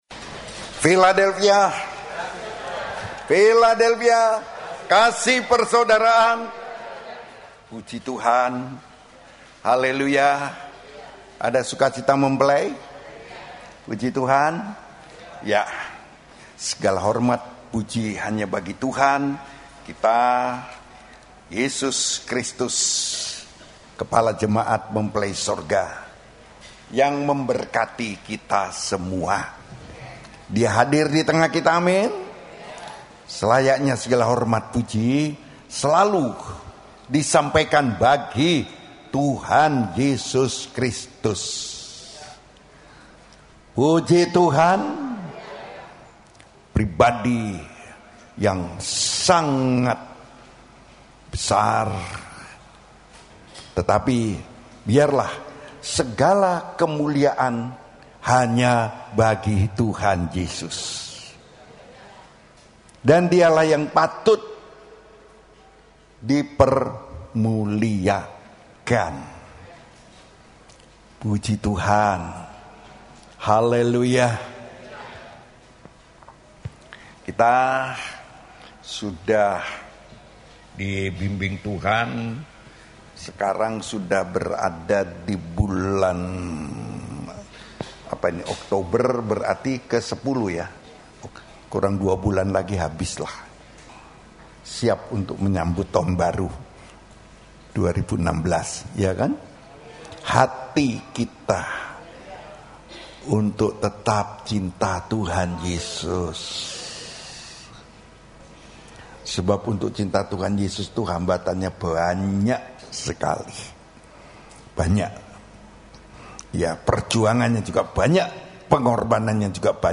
Khotbah Pengajaran